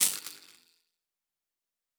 pgs/Assets/Audio/Sci-Fi Sounds/Electric/Spark 17.wav at master
Spark 17.wav